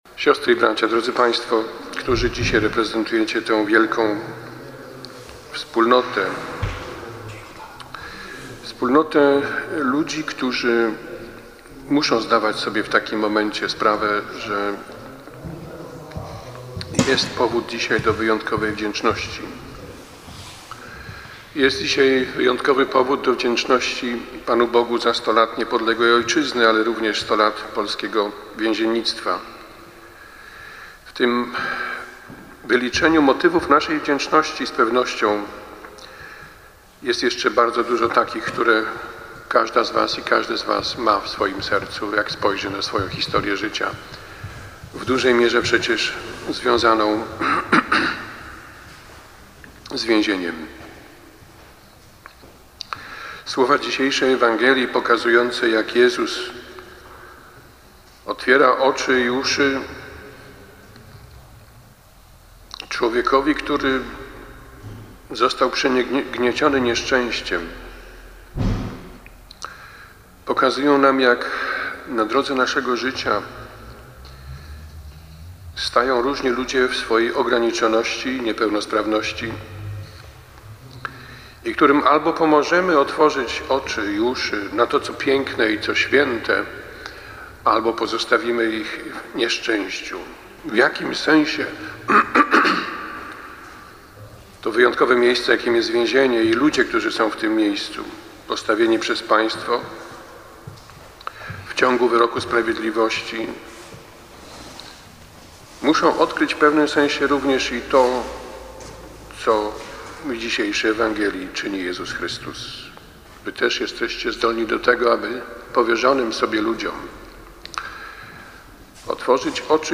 Bp Krzysztof Zadarko przewodniczył uroczystej Mszy św. w koszalińskiej katedrze z okazji 100-lecia Służby Więziennej.
Pierwsza część homilii